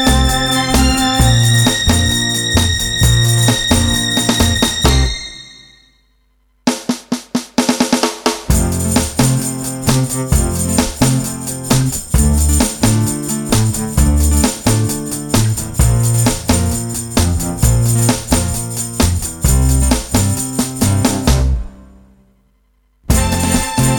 no Backing Vocals Soul / Motown 2:32 Buy £1.50